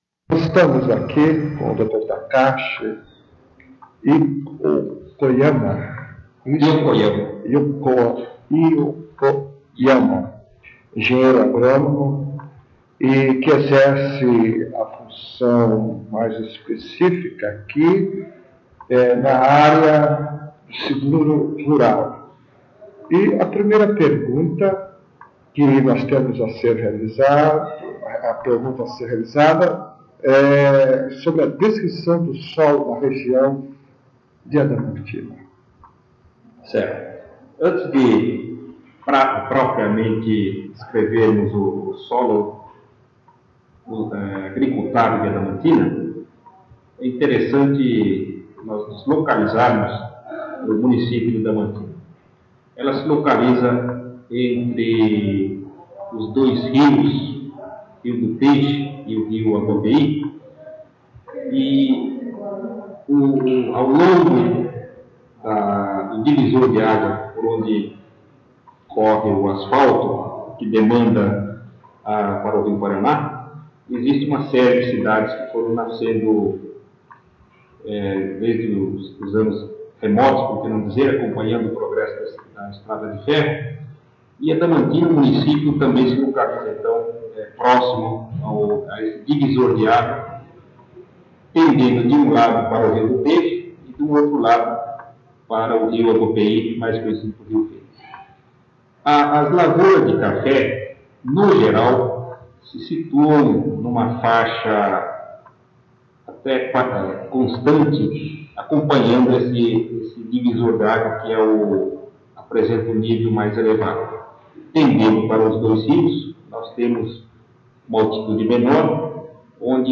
*Recomendado ouvir utilizando fones de ouvido.